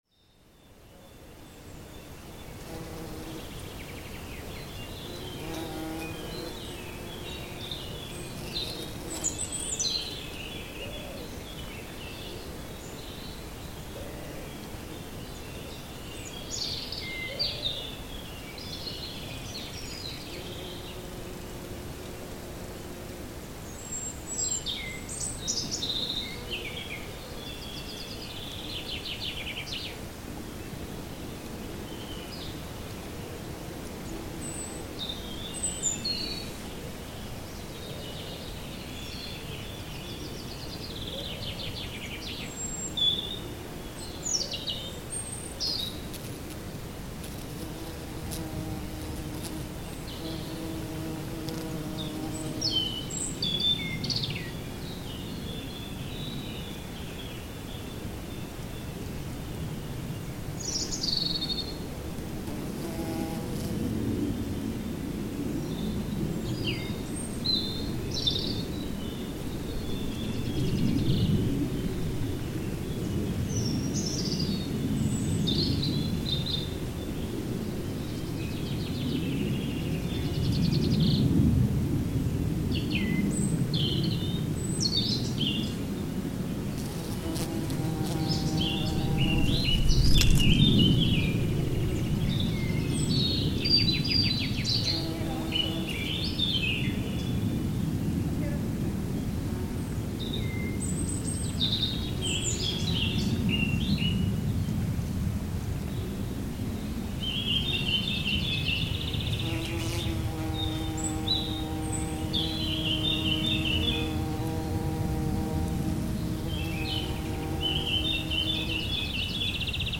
A lush spring soundscape from Monte Venda, the highest hill in northern Italy's Colli Euganei. You can hear bees feeding on nearby flowers, coming in close to the microphone, several species of birds overhead, and then the buzz of a small plane flying overhead in this short recording.